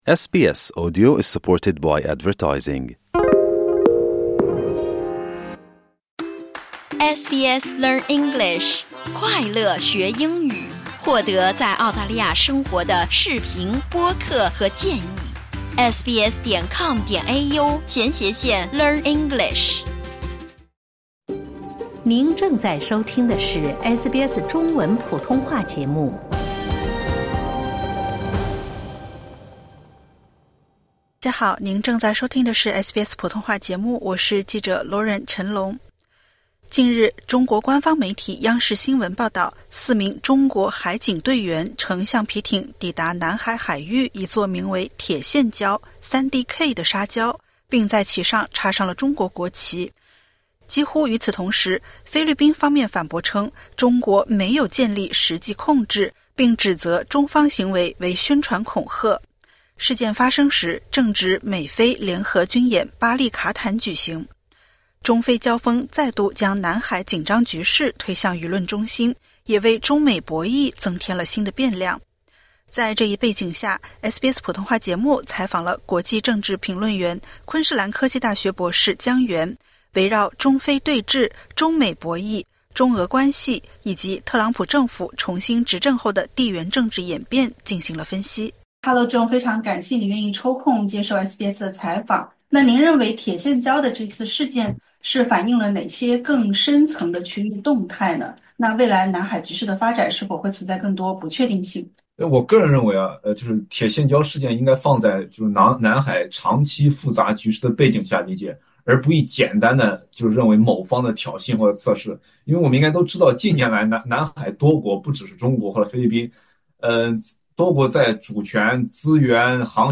近日，中国海警在南海铁线礁“插旗”。引发中菲争议。点击 ▶ 收听政治评论专家剖析事件背后的中美竞争格局、中俄关系演变及南海未来走向。